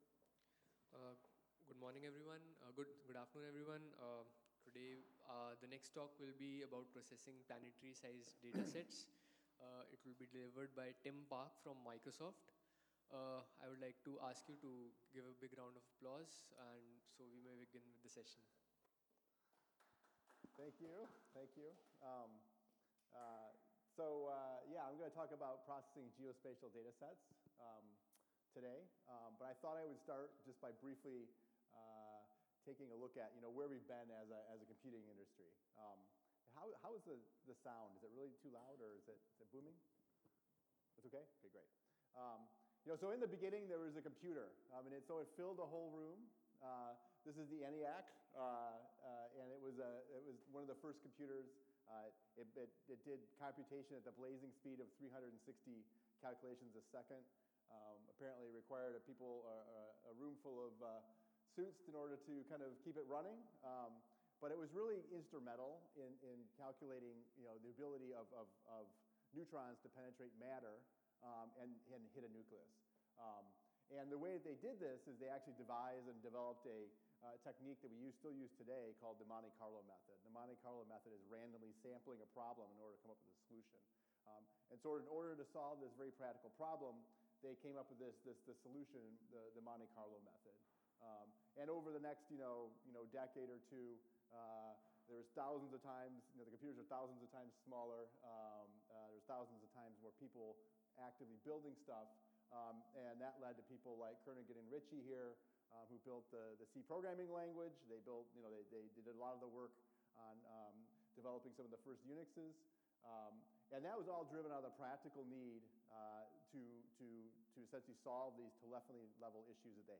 Apache Big Data EU 2016: Processing Planetary Sized Datasets